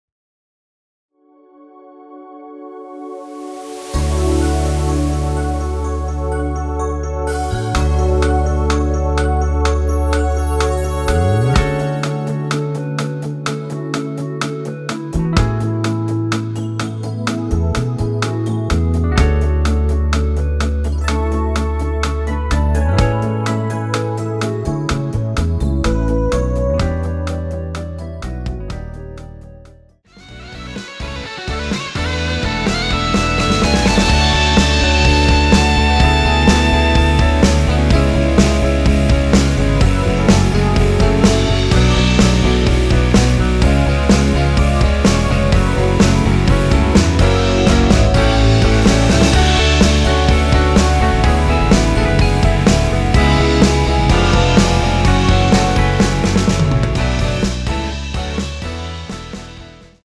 원키 멜로디 포함된 MR입니다.(미리듣기 확인)
앞부분30초, 뒷부분30초씩 편집해서 올려 드리고 있습니다.
중간에 음이 끈어지고 다시 나오는 이유는